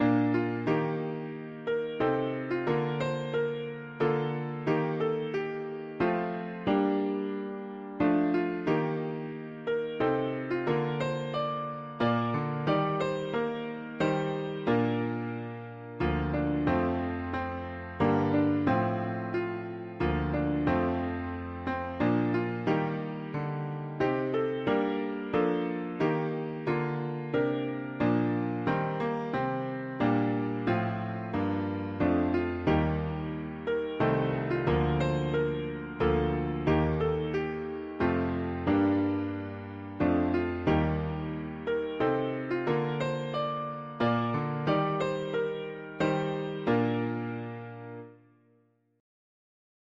Key: B-flat major